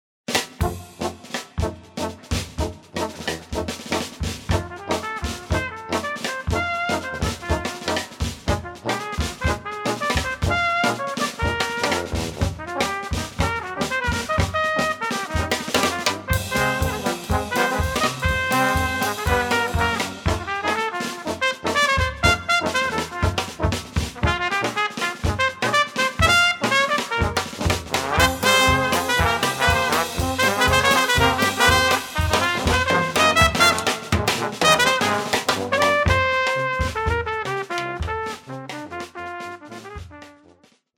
Swing, Jazz, Dixie mp3's: